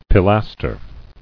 [pi·las·ter]